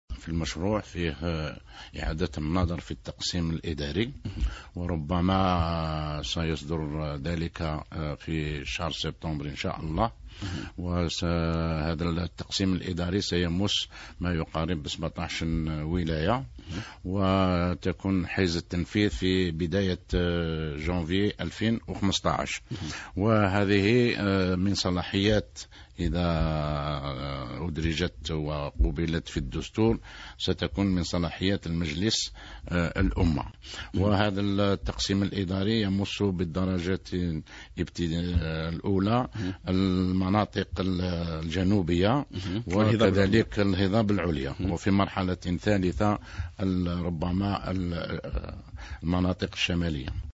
و أضاف خليل ماحي وزير العلاقات مع البرلمان، خلال نزوله هذا الخميس ضيفا على برنامج "ضيف الصباح" بالقناة الإذاعية الأولى، أن محاور المخطط التي تناقش في الجلسة العلنية بالمجلس الشعبي الوطني من الفاتح إلى الخامس جوان المقبل هي محور الاستقرار و المصالحة الوطنية و تكريس أركان القانون و الديمقراطية و كذا تطوير الاقتصاد الوطني و الاهتمام بكل القواعد التي تؤسس عليها العوامل من تنمية و تطوير و الاهتمام بالشباب و كذلك بإعادة النظر في التقسيم الإداري.